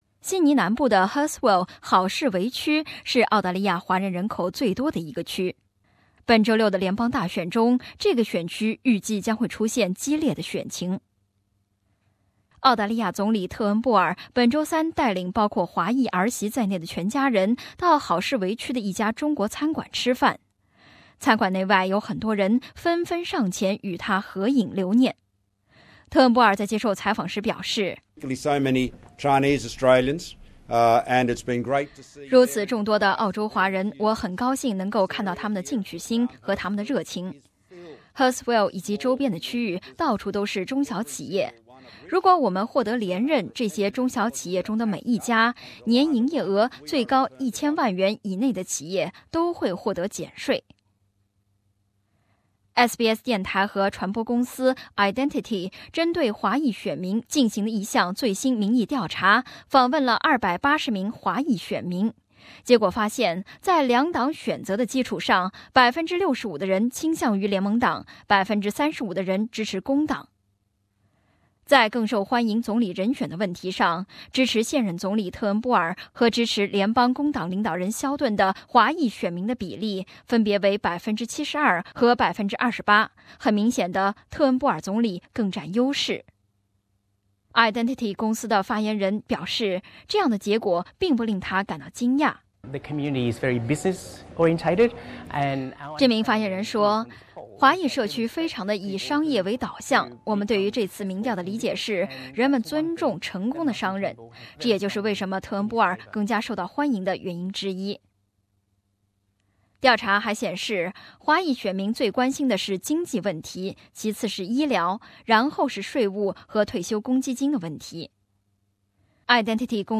那么这些华人选民会将手中的选票投给谁？请听本台的独家调查报道